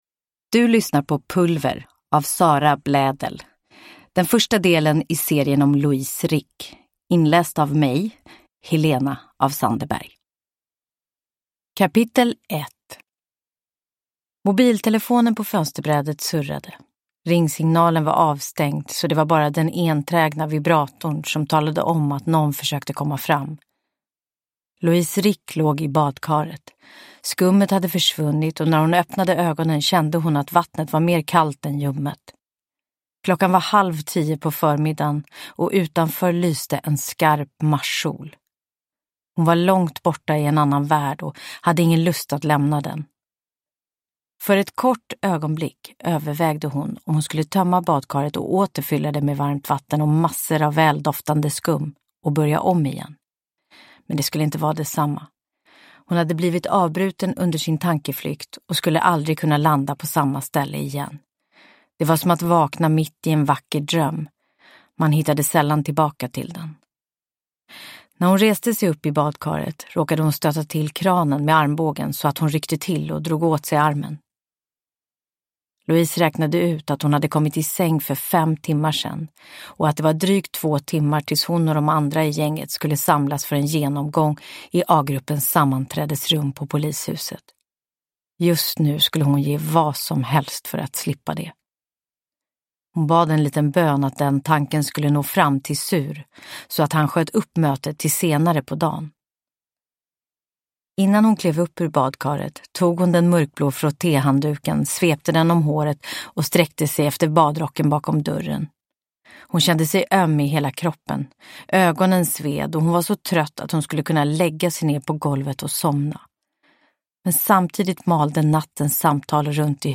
Pulver – Ljudbok – Laddas ner
Uppläsare: Helena af Sandeberg